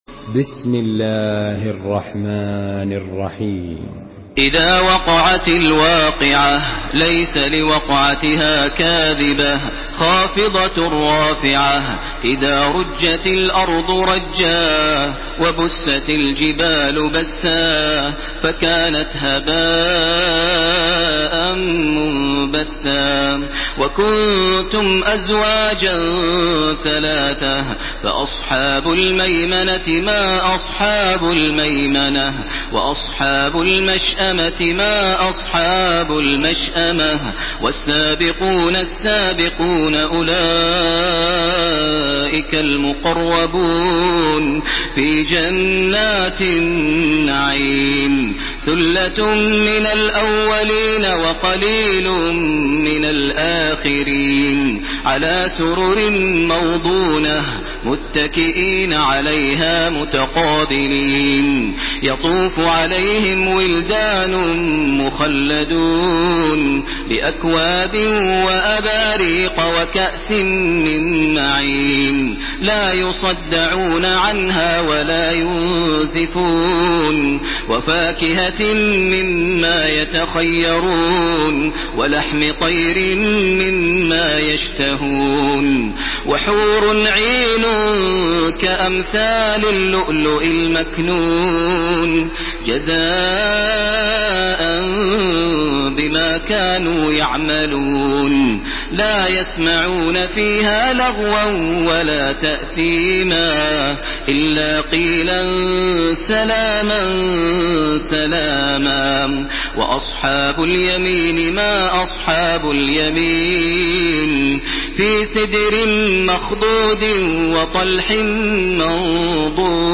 Surah Al-Waqiah MP3 by Makkah Taraweeh 1431 in Hafs An Asim narration.
Murattal Hafs An Asim